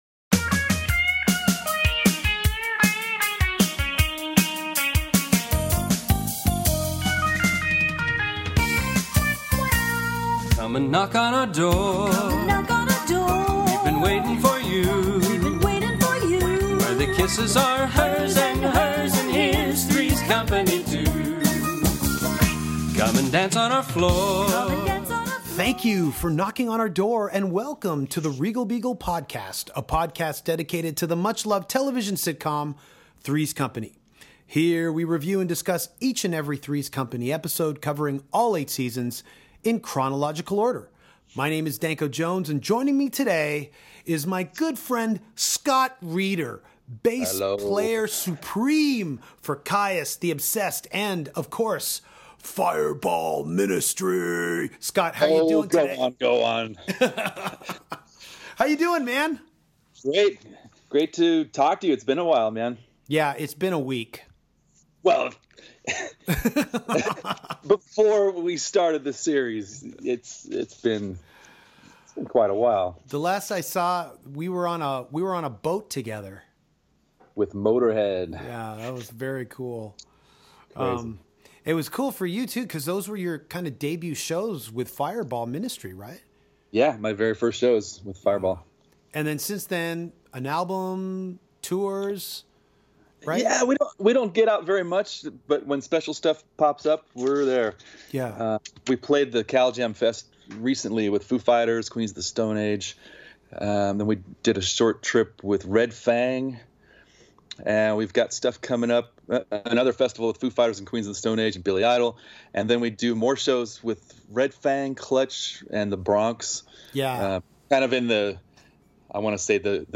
Danko co-host is Scott Reeder (Kyuss, Fireball Ministry, The Obsessed)